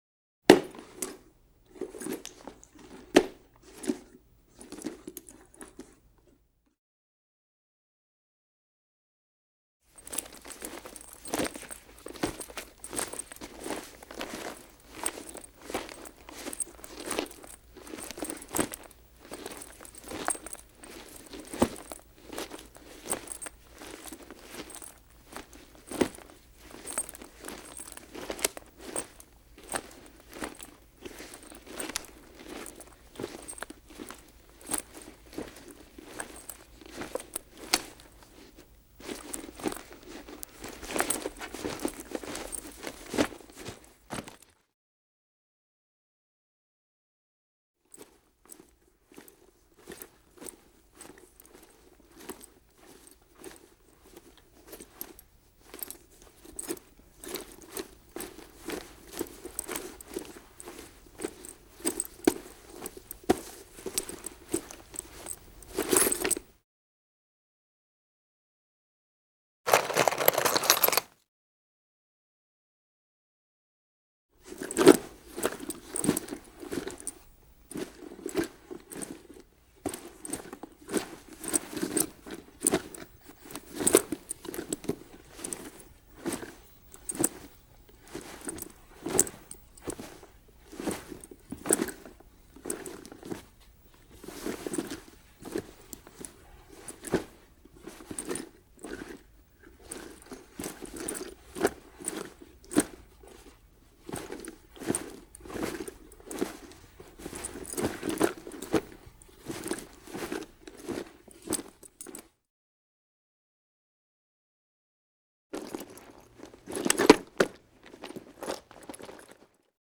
Hand Bag Sound
household